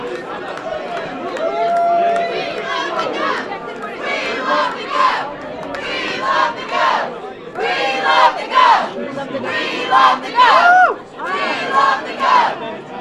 CROWD AT PURITAN CONFERENCE CENTER MANCHESTER NH AS RESULTS ARE BROADCAST SHOWING DEMOCRATS WINNING SENATE AND GOVERNORSHIP
Crowd at the Puritan Conference Center in Manchester reacts as results are broadcast showing Democrats winning the U.S. Senate race and the race for governor.
SOME-PARTY-GIRLS-START-A-CHEER-AT-THE-PURITAN-CENTER-IN-MANCHESTER-NH-WE-LOVE-THE-GOV.mp3